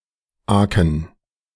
Aken (pronunciación en alemán:
De-Aken2.ogg.mp3